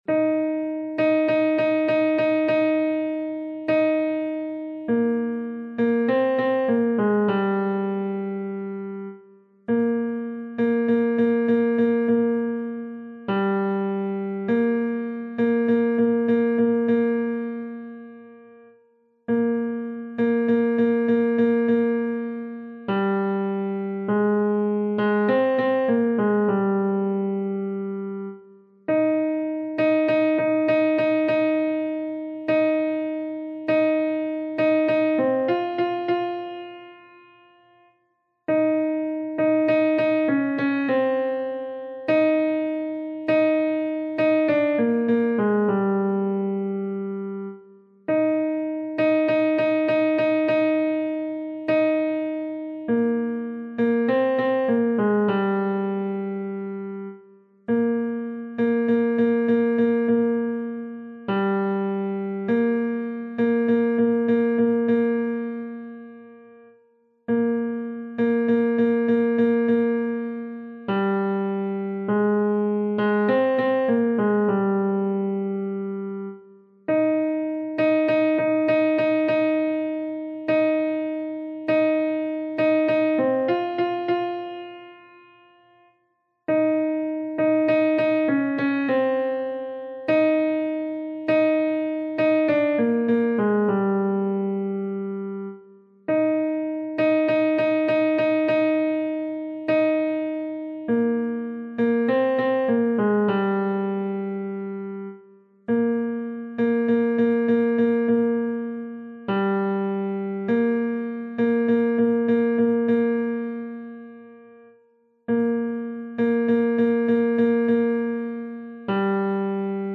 伴奏
男高